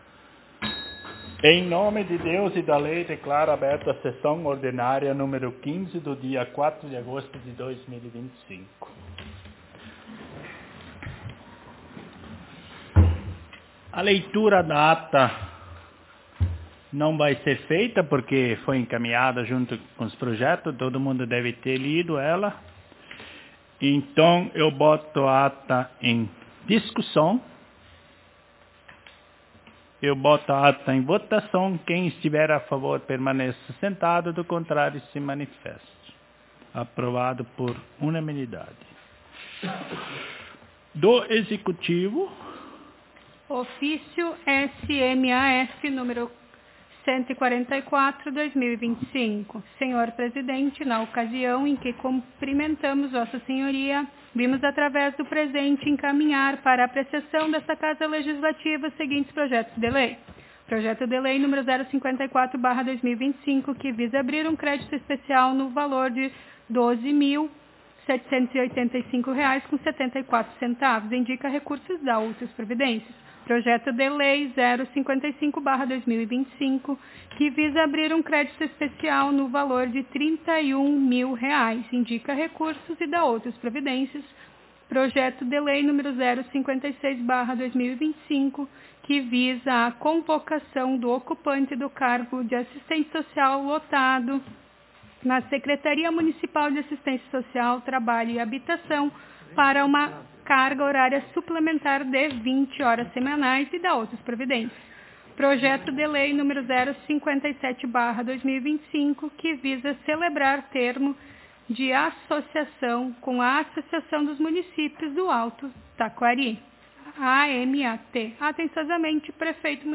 Aos 4 (quatro) dias do mês de agosto do ano de 2025 (dois mil e vinte e cinco), na Sala de Sessões da Câmara Municipal de Vereadores de Travesseiro/RS, realizou-se a Décima Quinta Sessão Ordinária da Legislatura 2025-2028.